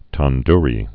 (tän-drē)